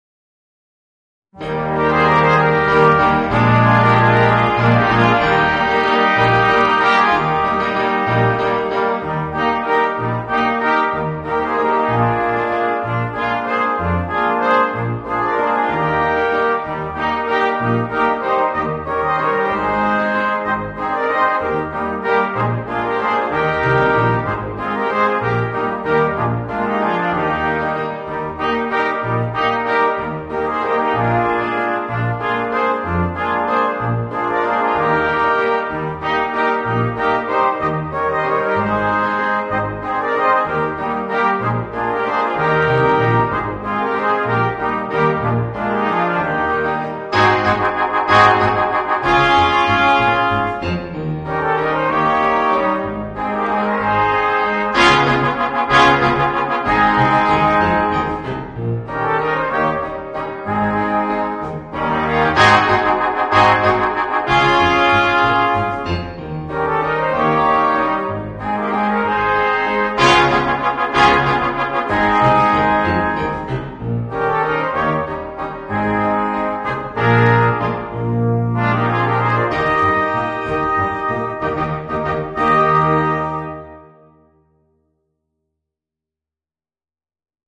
Voicing: 5 - Part Ensemble